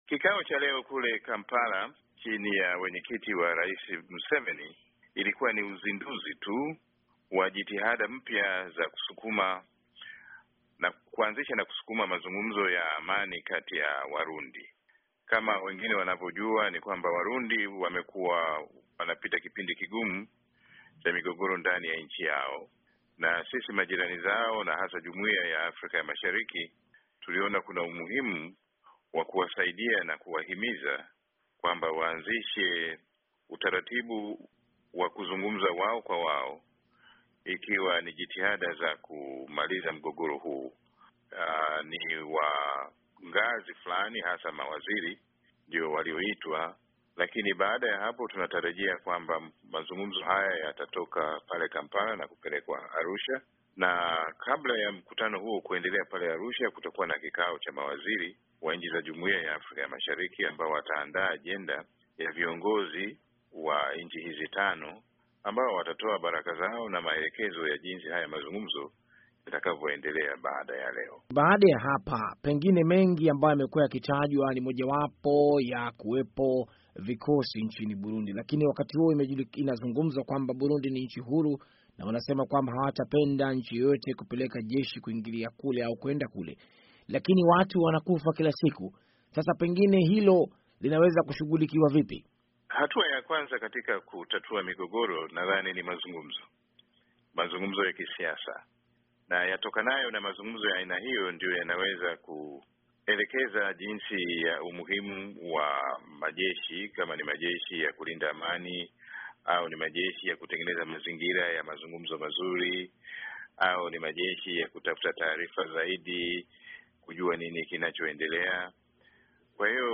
Mahojiano na Waziri Mahiga